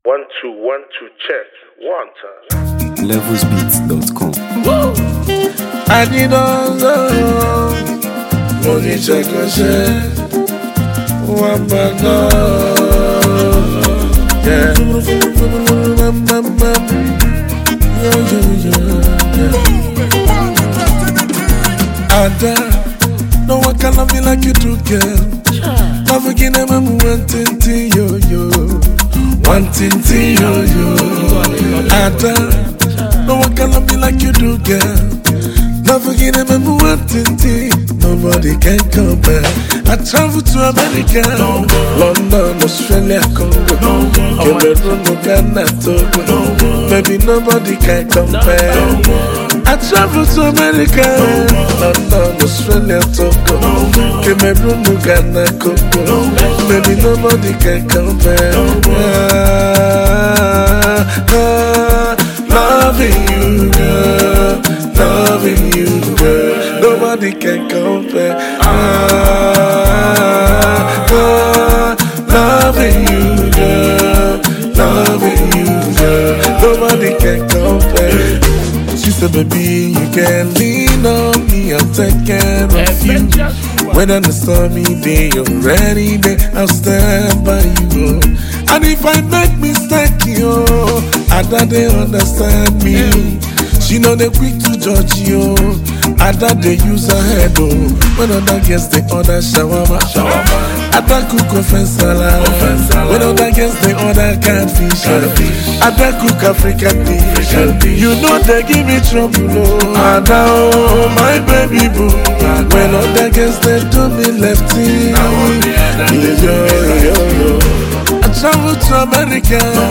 Nigerian highlife
soulful and heartfelt track